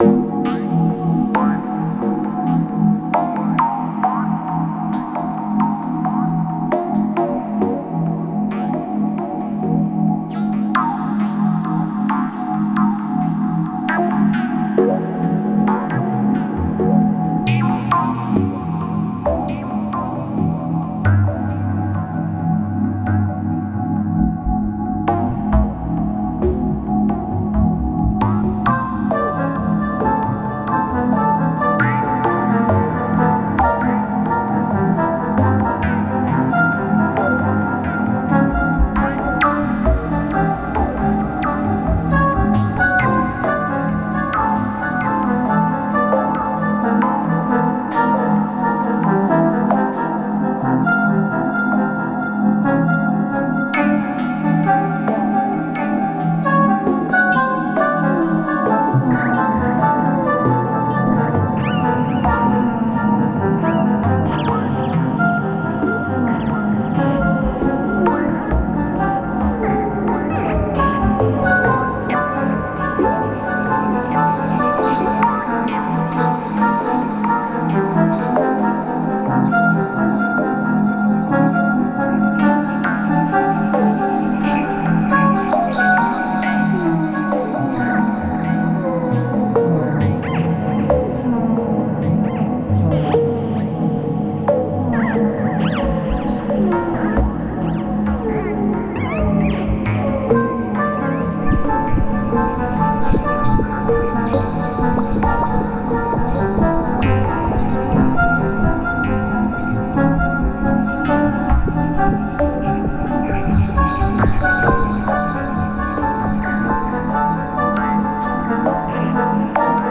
Genre: Industrial.